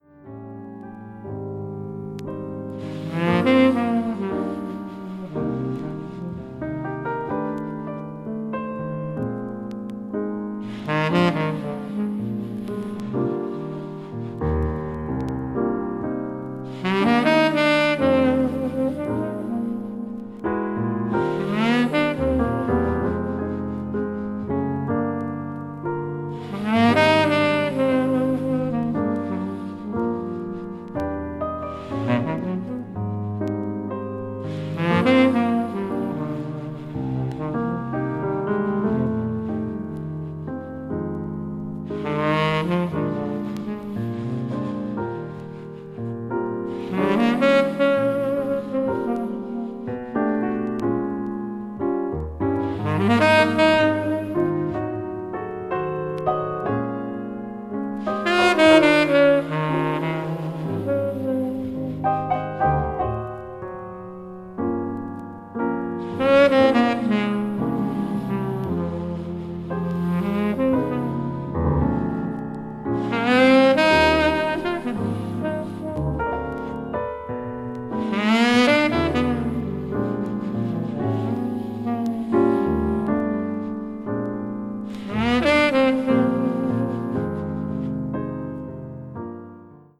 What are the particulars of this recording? featuring clear mid-to-high frequencies and rich low-end.